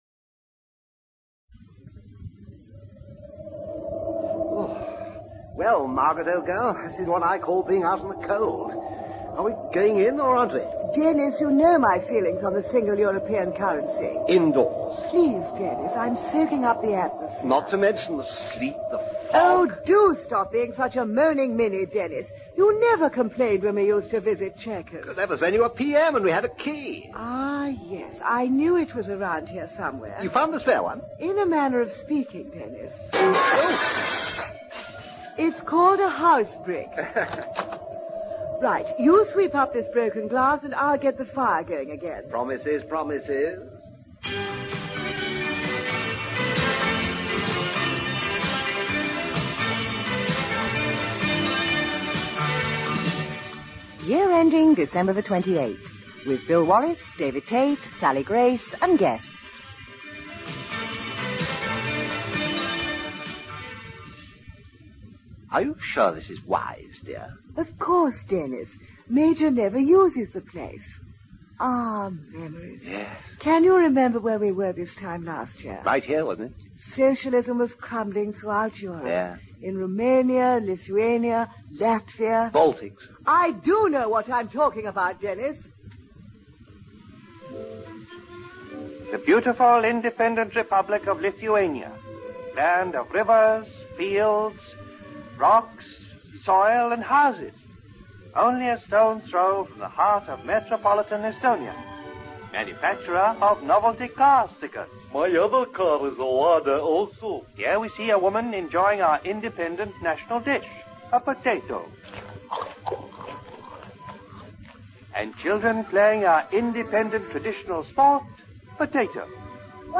The weekly satirical sketch show in which often promising young writers, like Harry Hill and David Baddiel, and great impressionists and actors like David Jason, Jon Glover, Sally Grace, Alison Steadman and Tracey Ullman came together with their humorous take on the week’s news.